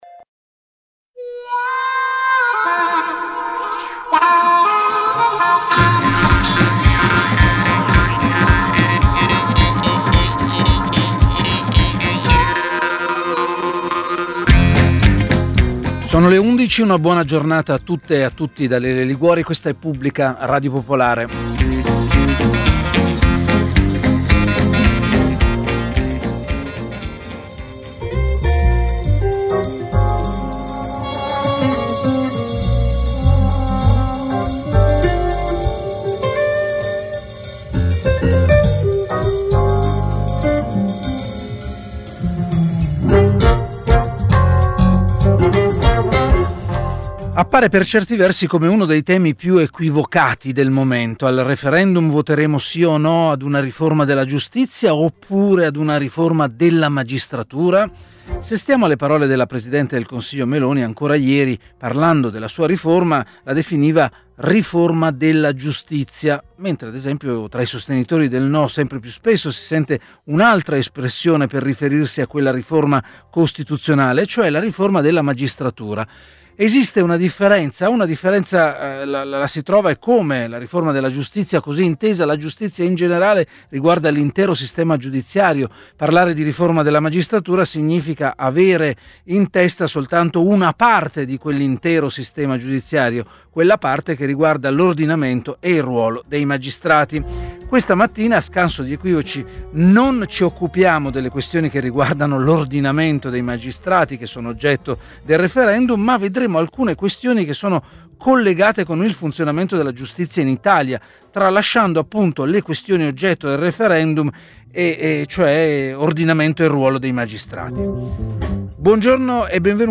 Pubblica ha ospitato oggi il racconto di una magistrata e di un magistrato: Franca Imbergamo, sostituta procuratrice presso la Direzione nazionale antimafia e antiterrorismo; e Alfonso Sabella, giudice al Tribunale di Roma.